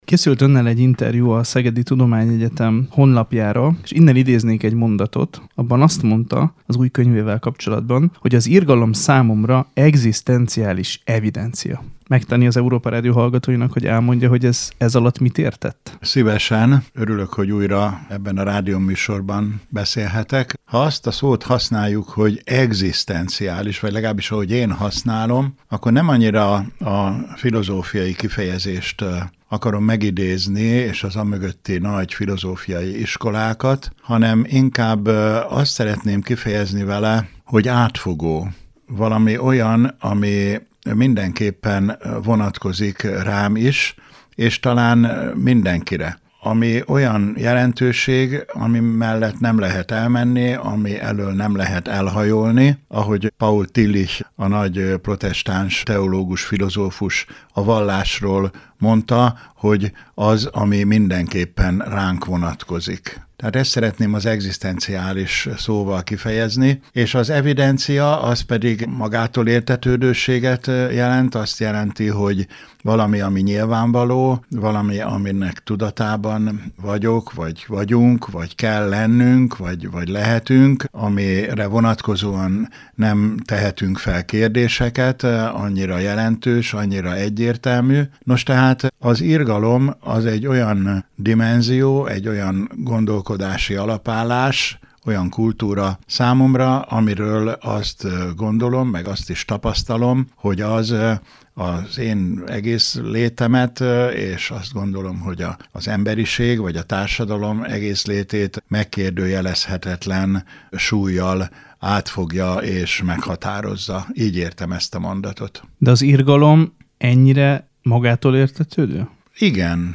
A református értékadó!